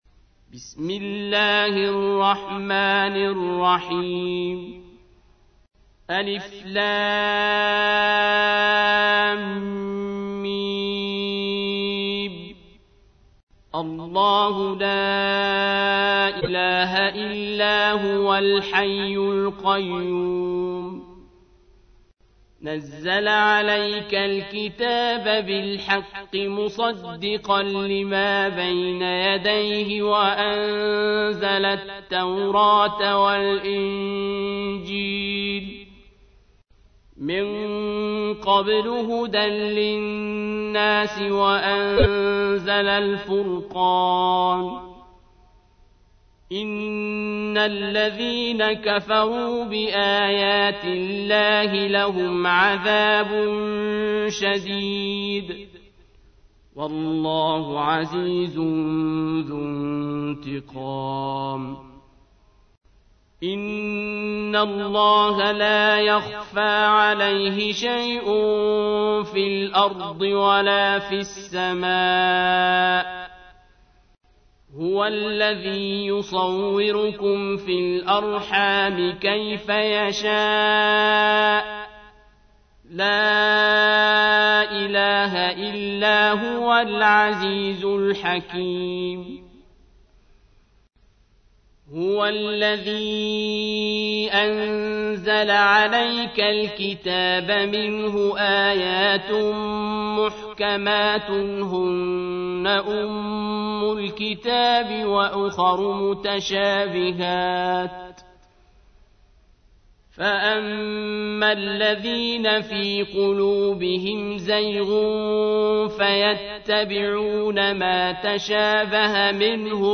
تحميل : 3. سورة آل عمران / القارئ عبد الباسط عبد الصمد / القرآن الكريم / موقع يا حسين